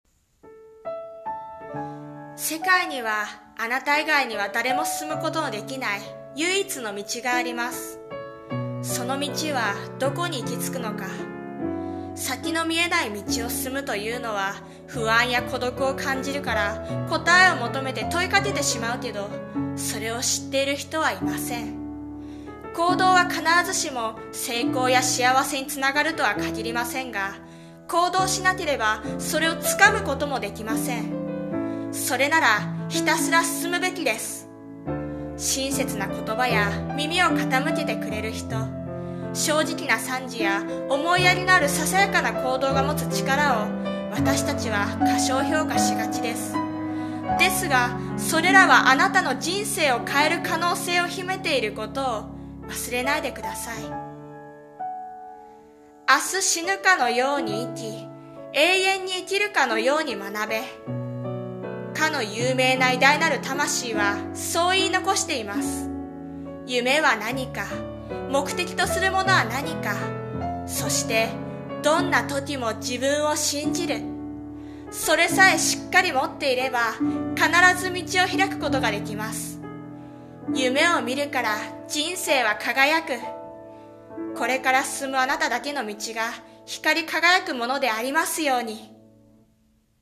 【声劇】夢を見るから、人生は輝く